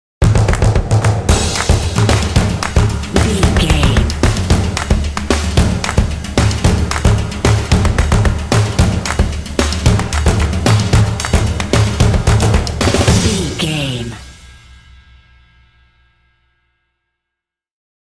Epic / Action
Atonal
confident
tension
percussion
drumline